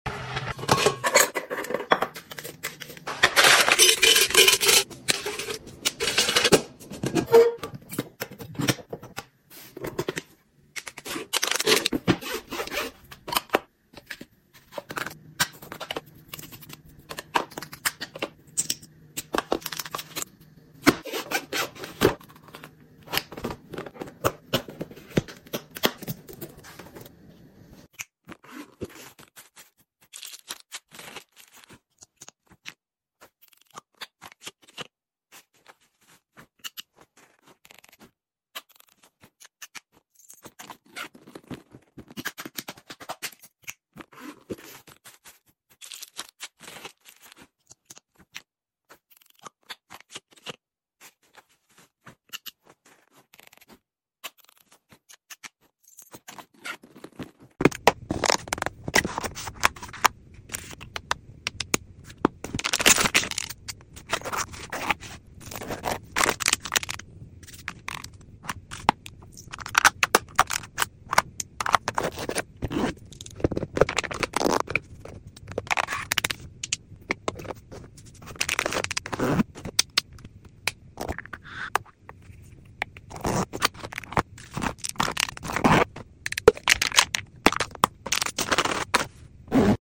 $2000 Of Packing Asmr Sound Effects Free Download